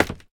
minecraft / sounds / step / scaffold6.ogg
scaffold6.ogg